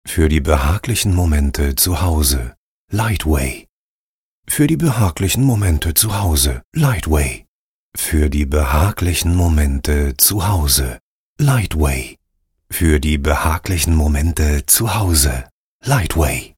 Sprechprobe: Werbung (Muttersprache):
Lightway - Werbung, Abbinder Variationen.mp3